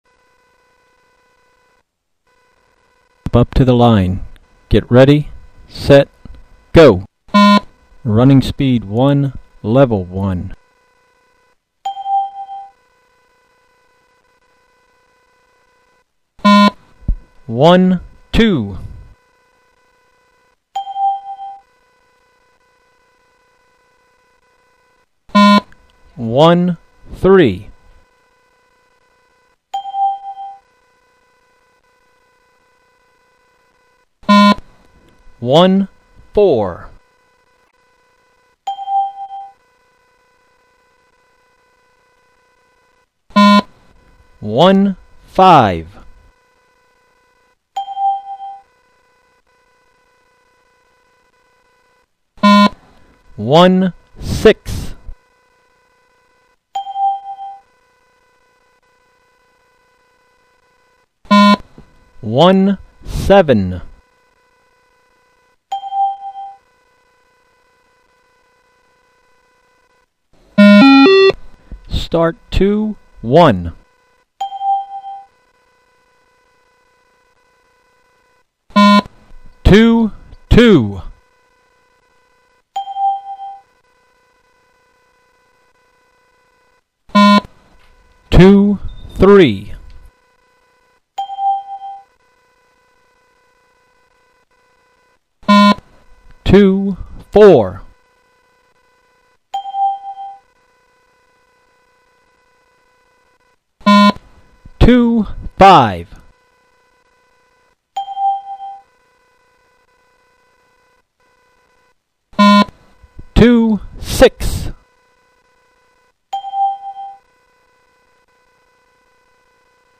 The Yo-Yo involves running between two markers, 20m apart, and another marker 5m in front if the start. Audio prompts dictate the running speed required.